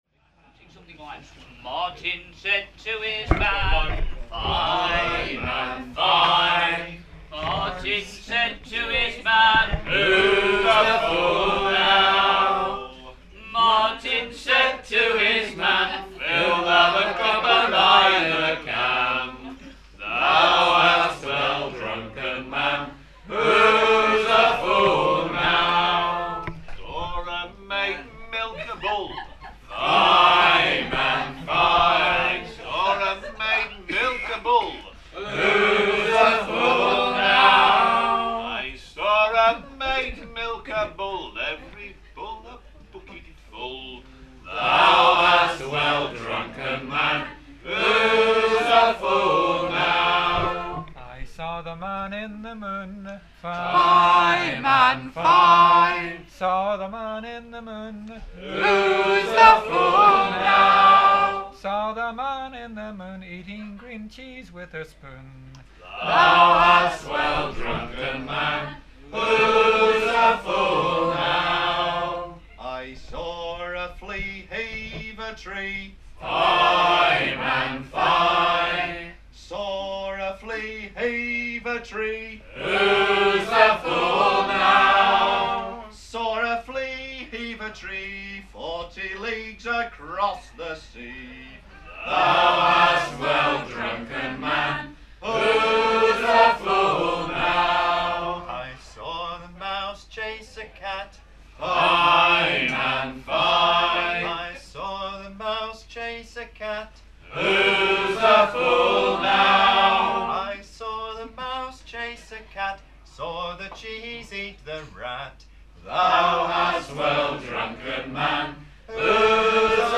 Morris songs